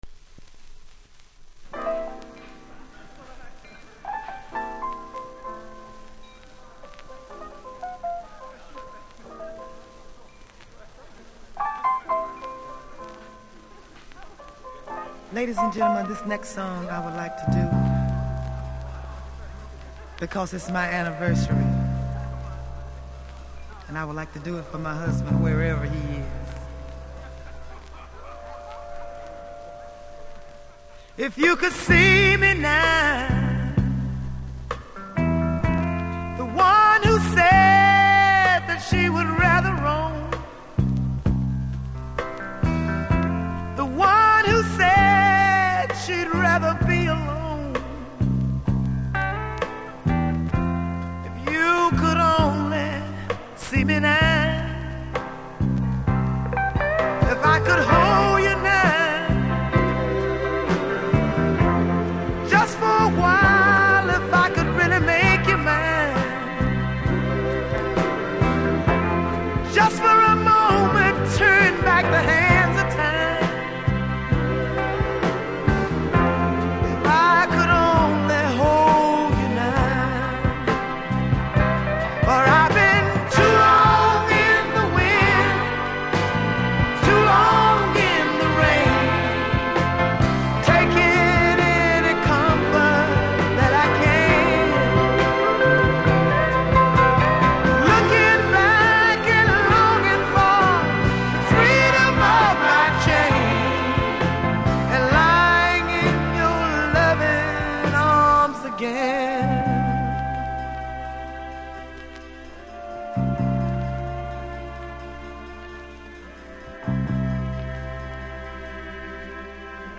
Vinyl
少しプレス起因のノイズ感じますので試聴で確認下さい。